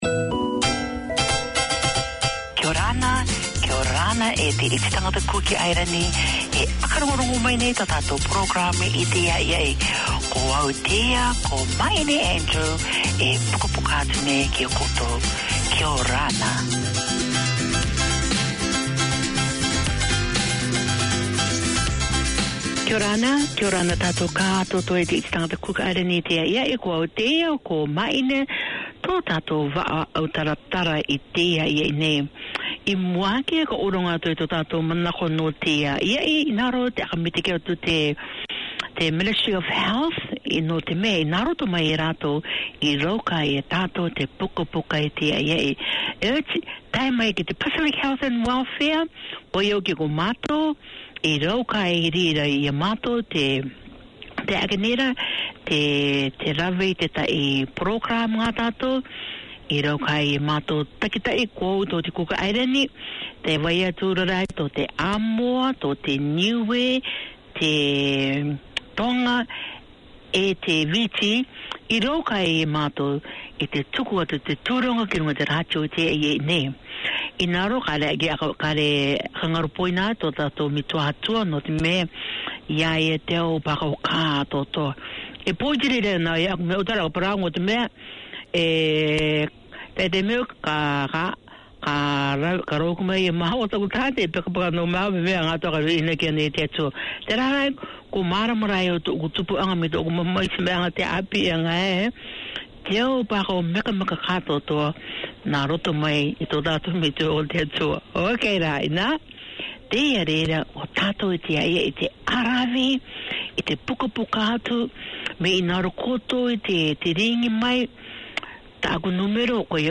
An outreach of the Pacific Islands Health and Welfare project under the auspices of the Auckland Health Board, Cook Islands Health is the half hour each week that keeps you in touch with health news, with interviews, information, community updates.